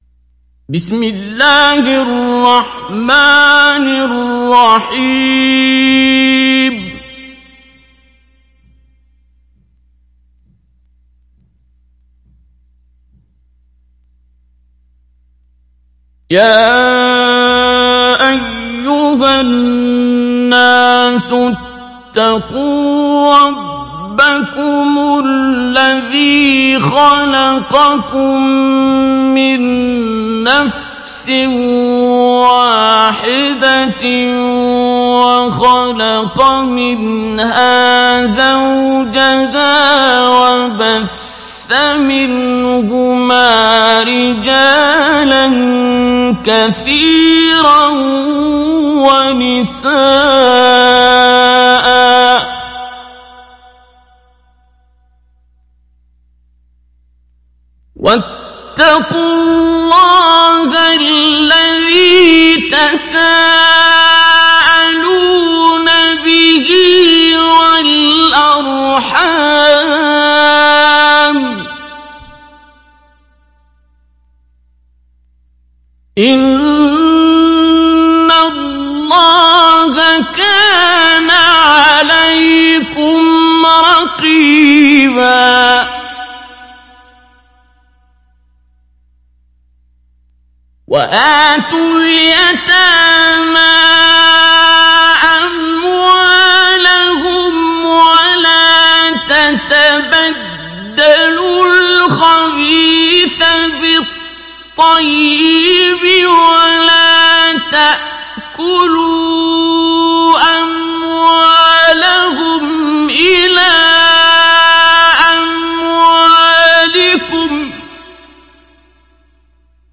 تحميل سورة النساء mp3 بصوت عبد الباسط عبد الصمد مجود برواية حفص عن عاصم, تحميل استماع القرآن الكريم على الجوال mp3 كاملا بروابط مباشرة وسريعة
تحميل سورة النساء عبد الباسط عبد الصمد مجود